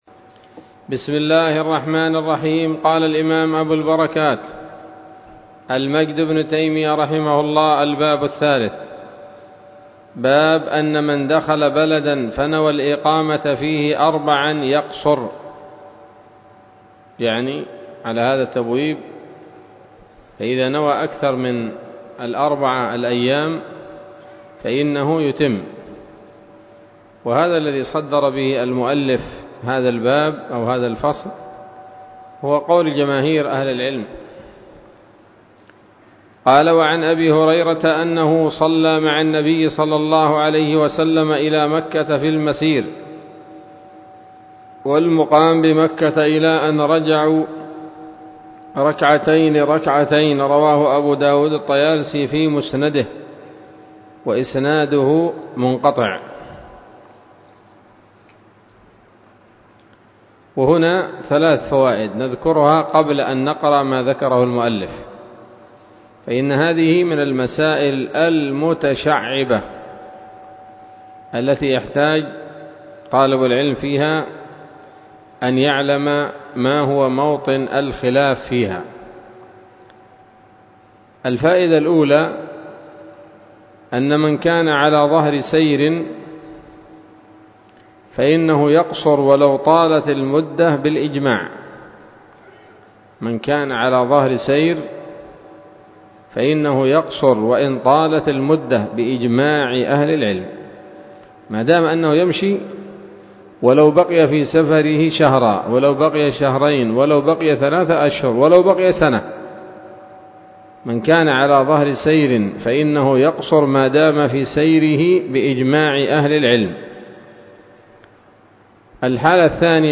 الدرس الخامس من ‌‌‌‌أَبْوَاب صَلَاةِ الْمُسَافِرِ من نيل الأوطار